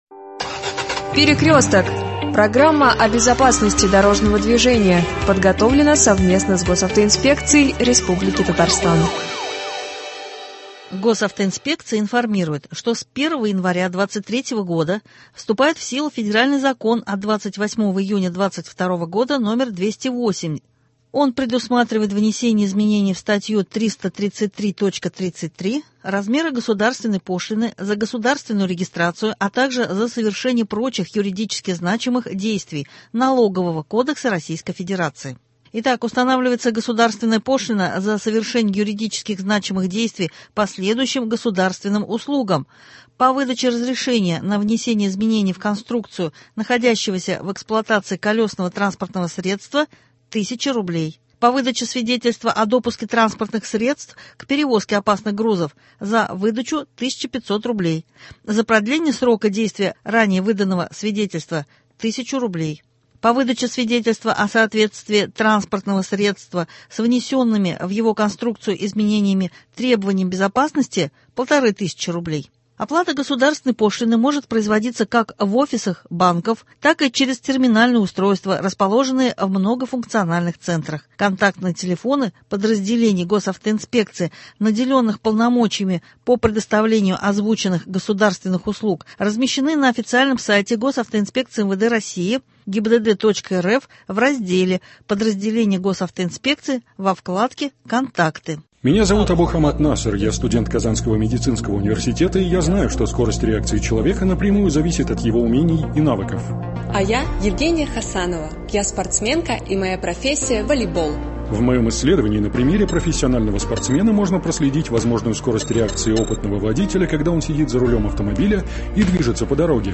А сейчас- записи с итоговой пресс конференции министра транспорта и дорожного хозяйства РТ Фарта Ханифова